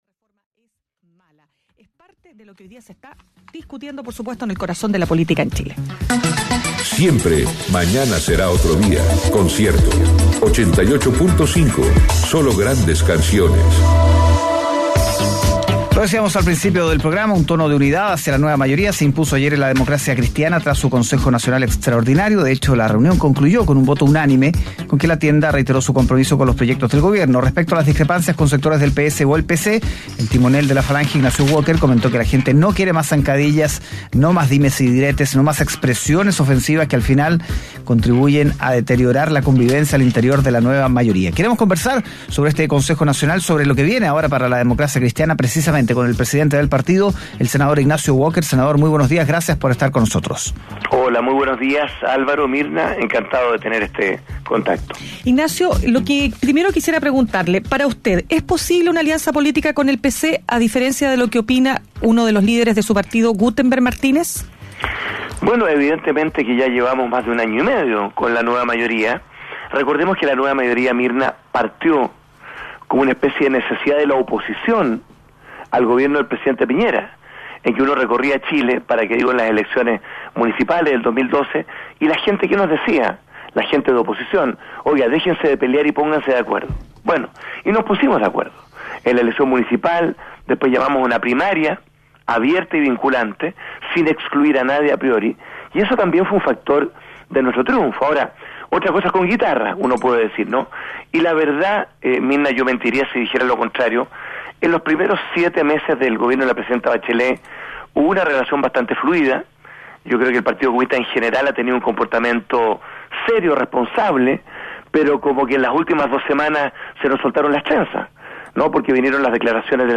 Escucha la entrevista realizada en Mañana Será Otro Día: